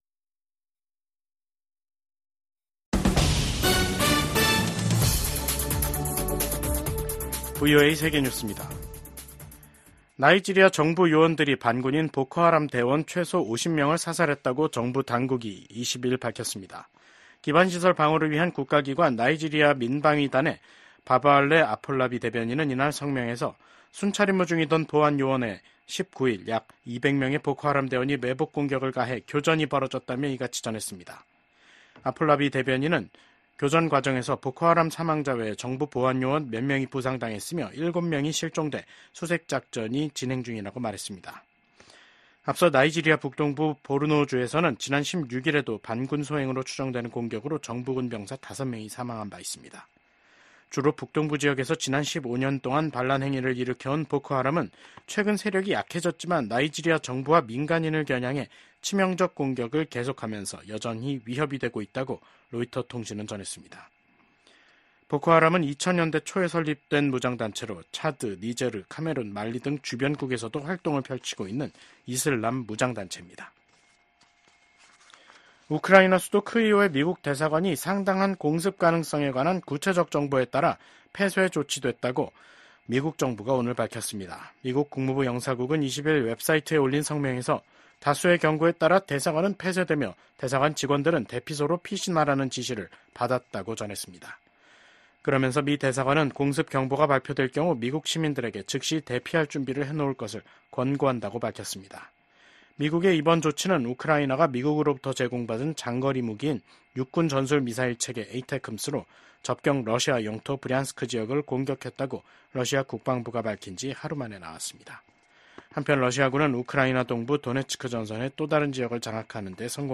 VOA 한국어 간판 뉴스 프로그램 '뉴스 투데이', 2024년 11월 20일 3부 방송입니다. 한국 정보 당국은 북한 군이 러시아 군에 배속돼 우크라이나 전쟁에 참여하고 있다고 밝혔습니다. 미국 국방부는 러시아의 우크라이나 침략 전쟁에 참전하는 북한군은 정당한 합법적인 공격 목표가 될 것이라고 재차 경고했습니다. 미국의 우크라이나 전문가들은 북한군 파병이 러시아-우크라이나 전쟁에 미치는 영향이 제한적인 것이라고 전망했습니다.